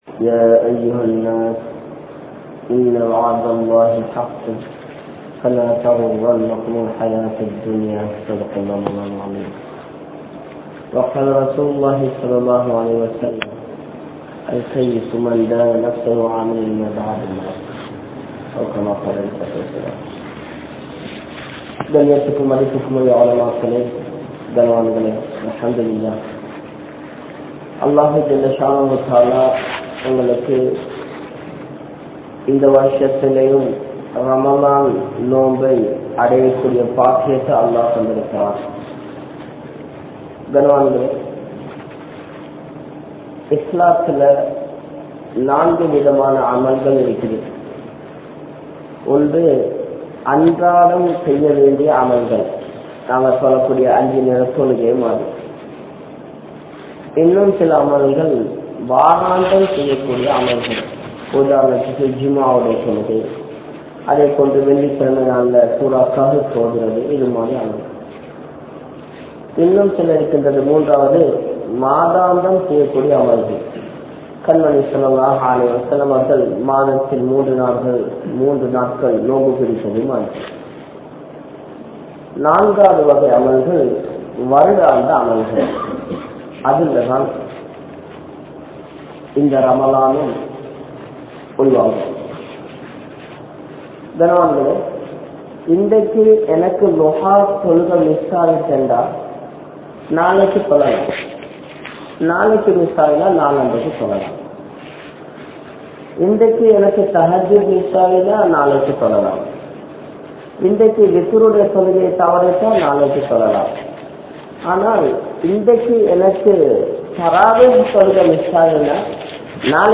Paarvaiyai Paathuhaarungal (பார்வையை பாதுகாருங்கள்) | Audio Bayans | All Ceylon Muslim Youth Community | Addalaichenai